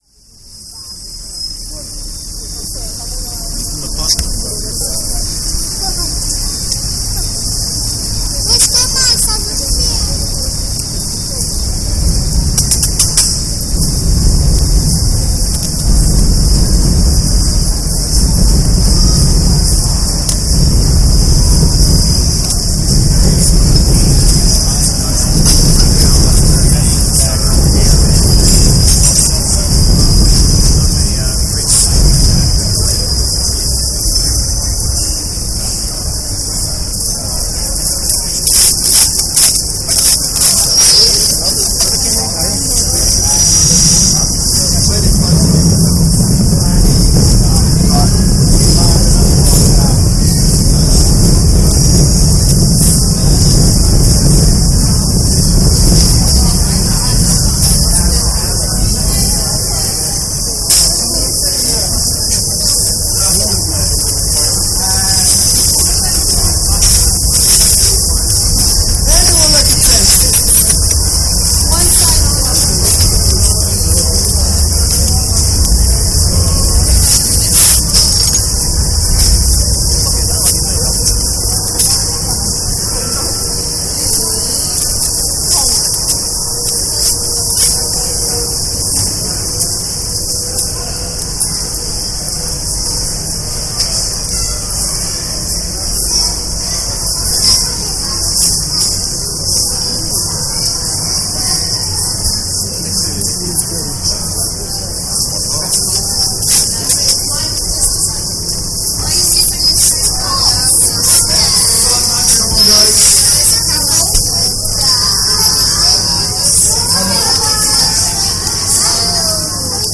An extreme low-end and high-end channel remix of Borough Market.